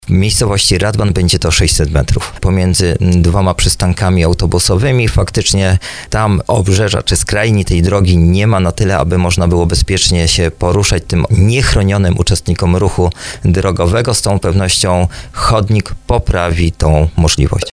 Mieszkańcy w końcu będą mogli poczuć się bezpiecznie – mówił burmistrz Szczucina Tomasz Bełzowski w programie 'Słowo za Słowo”.